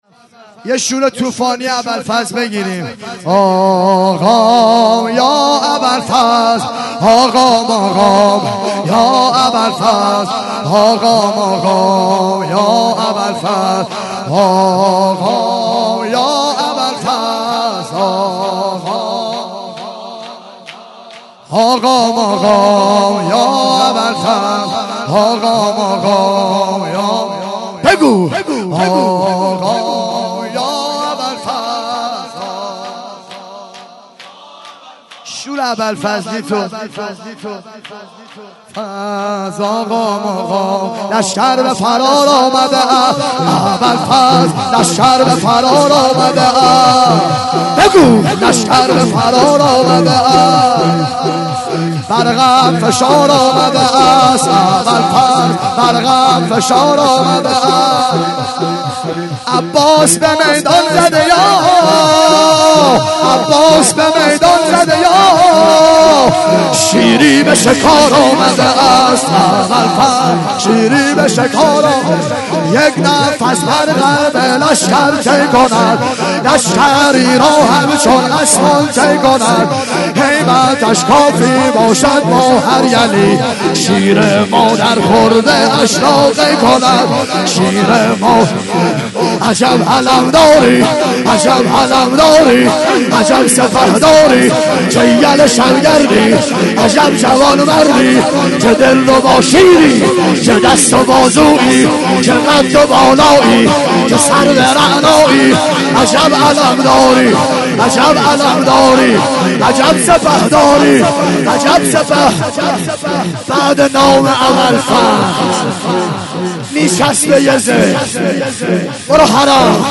12- لشکر به فرار آمده است اباالفضل - شور